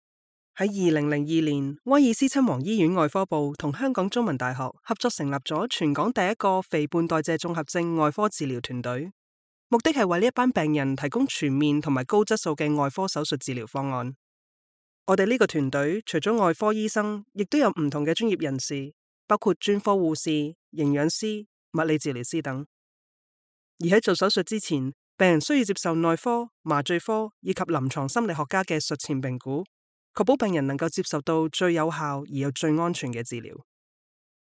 Corporate Videos
Her warm female tone is great for both professional narrations and positive representation for your brand!